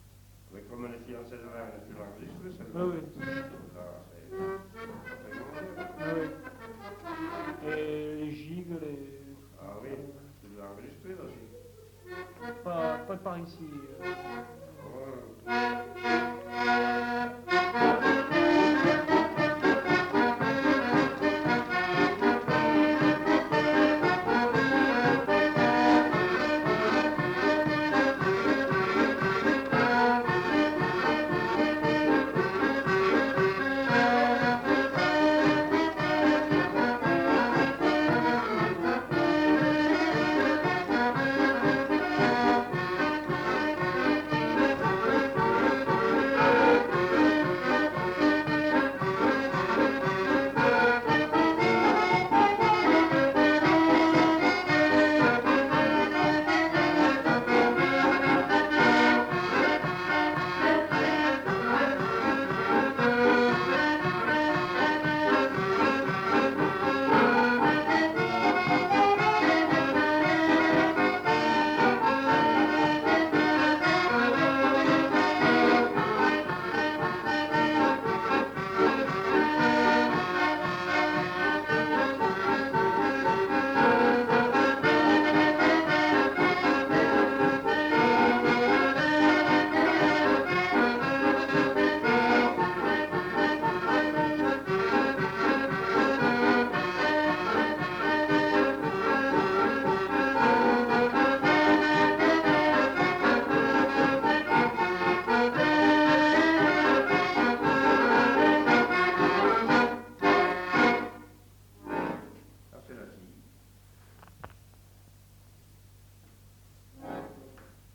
Aire culturelle : Viadène
Lieu : La Capelle (lieu-dit)
Genre : morceau instrumental
Instrument de musique : accordéon chromatique
Danse : gigue